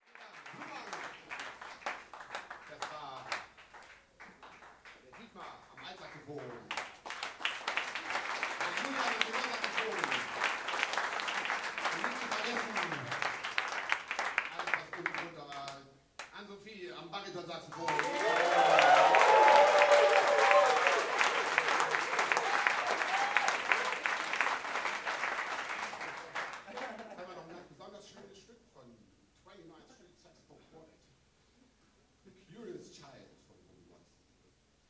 Ansage